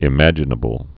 (ĭ-măjə-nə-bəl)